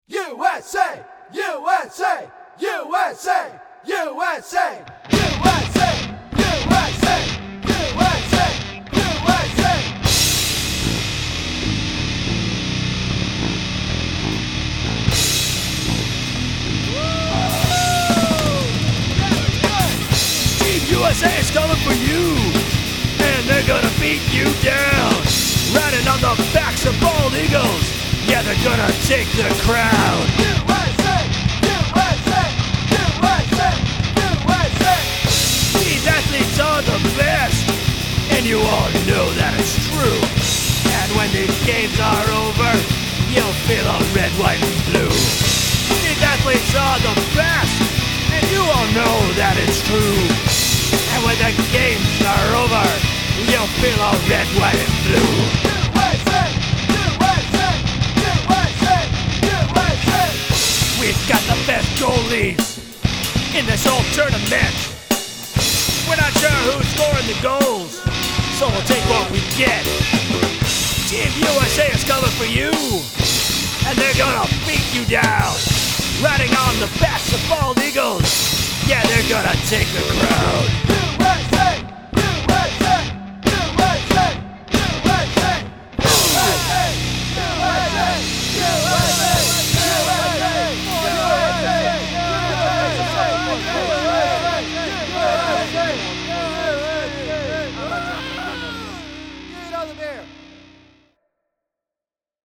I decided, at the start of the olympic hockey tournament, that I should write a silly Team USA anthem. To further add to the ridiculousness, I decided to channel my inner pro wrestler for the lead vocal – Not surprisingly, my throat hurt for a while after I recorded this song…
The music is just a big ol’ slice of metal.